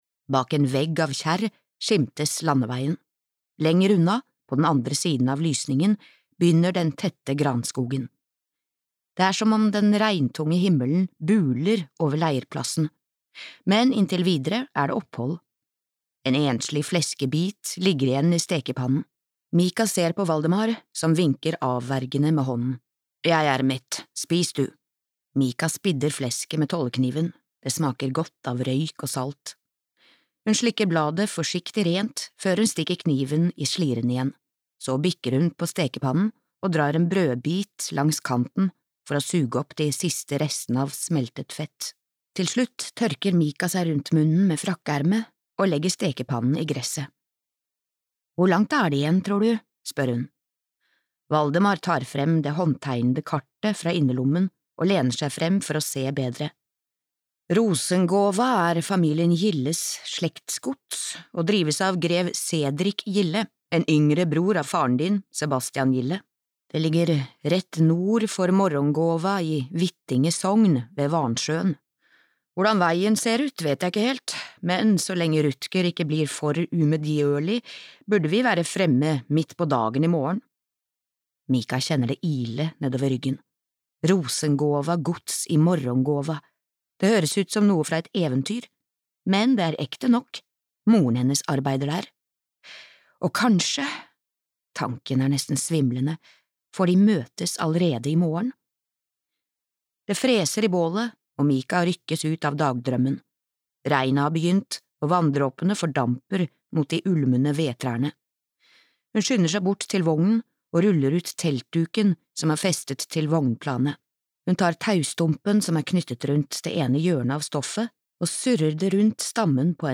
Blodspakten (lydbok) av Johan Rundberg